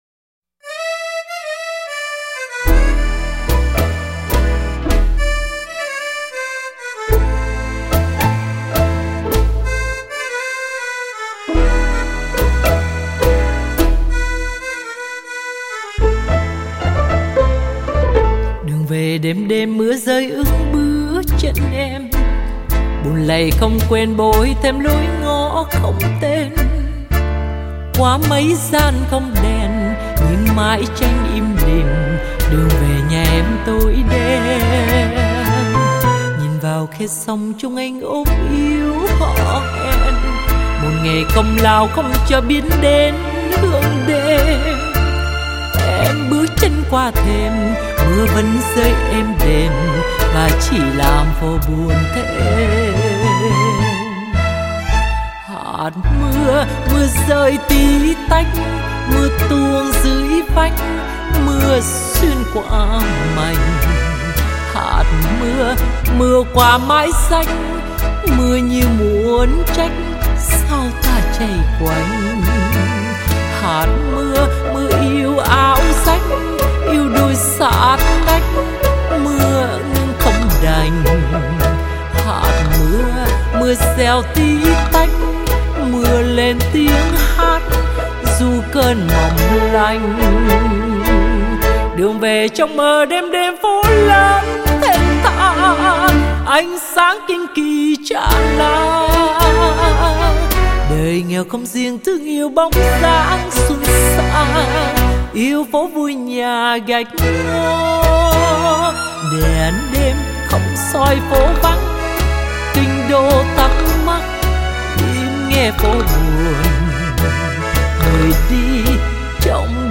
NỮ DANH CA